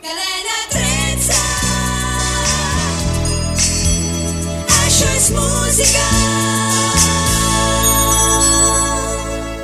Indicatiu de la Cadena i de la radiofòrmula